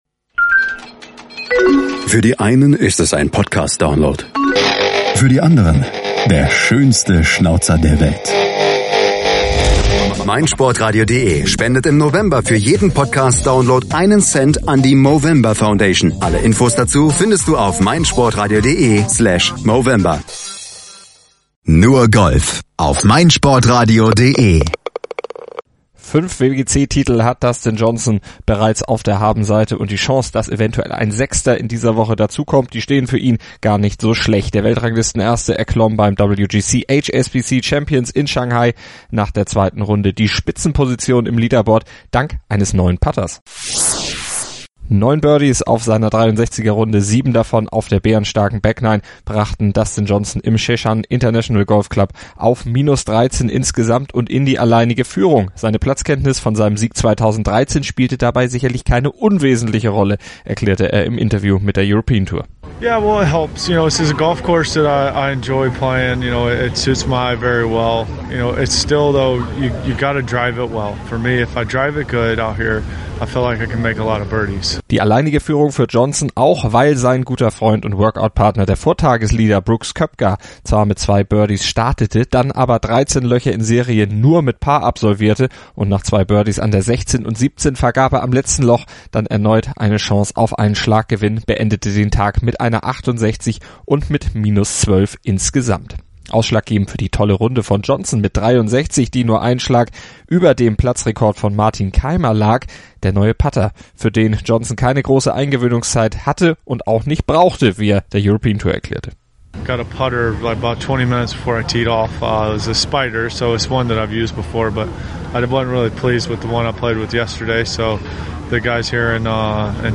zusammen und lässt Johnson berichten.